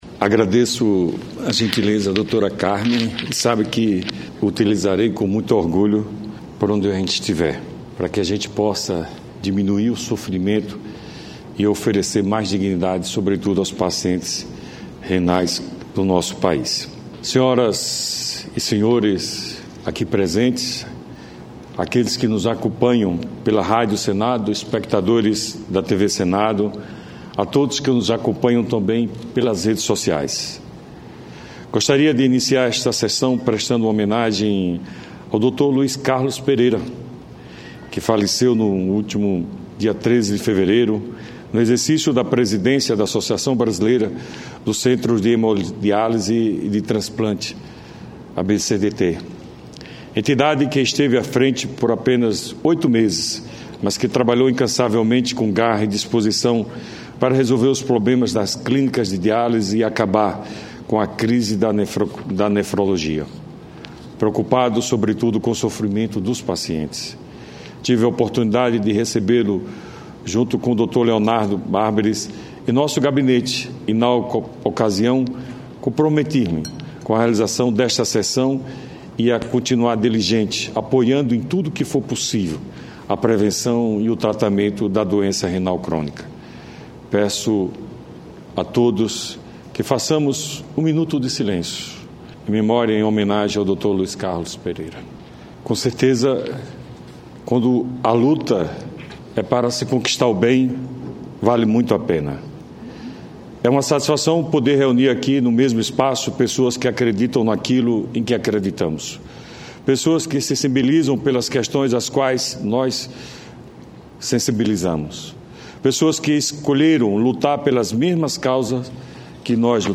Pronunciamento do senador Eduardo Amorim